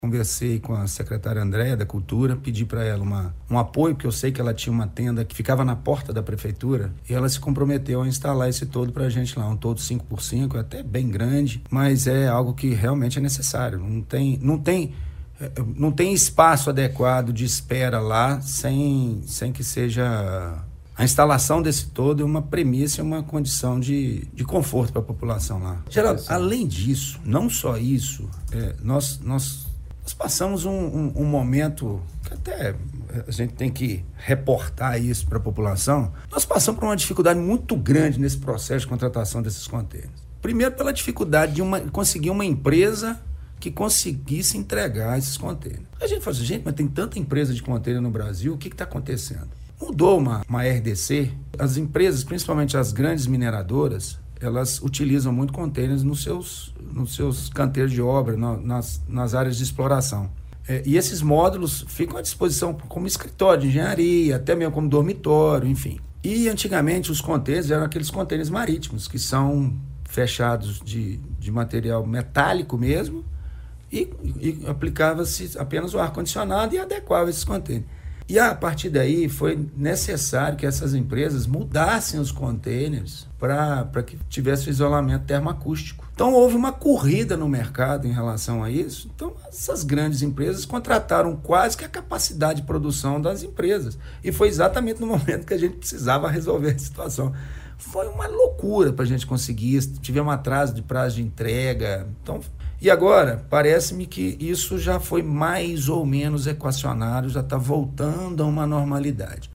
O secretário municipal de Saúde, Wagner Magesty, explicou ao Portal GRNEWS porque demorou tanto para que essa unidade modular com contêineres entrasse em funcionamento. Um dos motivos foi o aumento inesperado por esses equipamentos na mesma época em que Pará de Minas precisou.